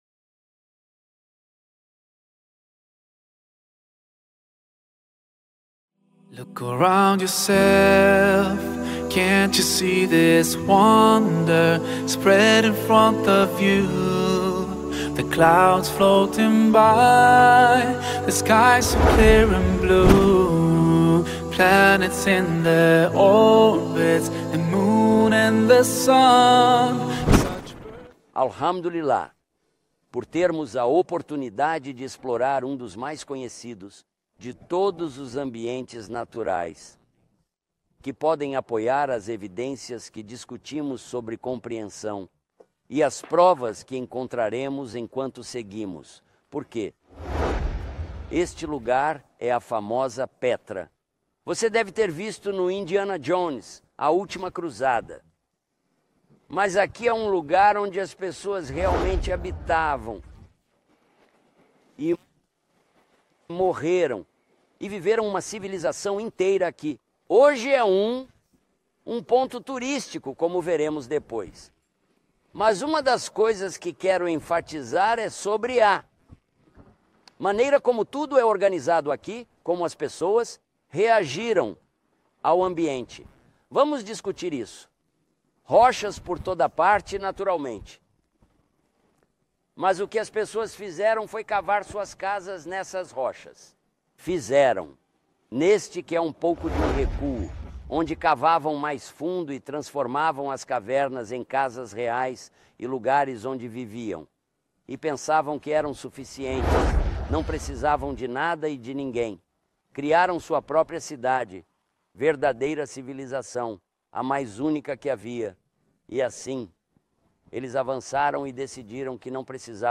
filmada com paisagens cênicas e locais históricos na Jordânia. Neste episódio, ele explica o primeiro pilar da fé – a crença em Deus.